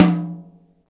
TOM XTOMHI09.wav